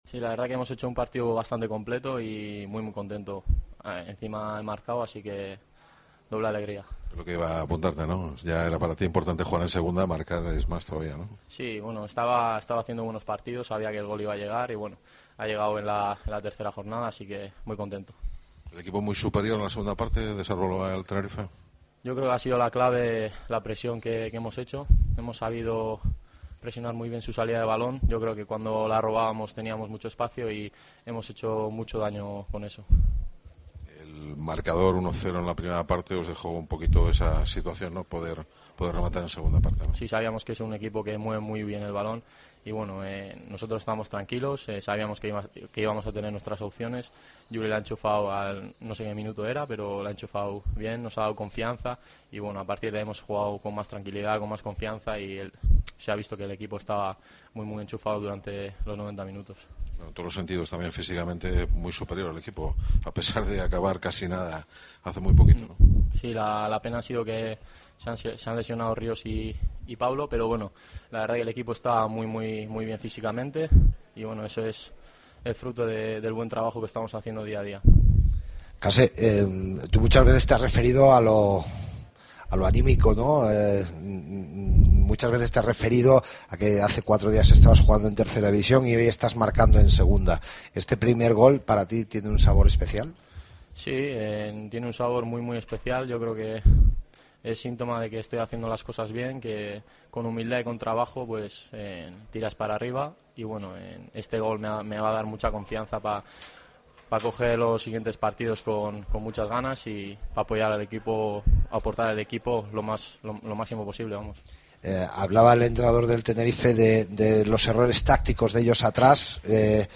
Escucha aquí las palabras de los dos futbolistas de la escuadra berciana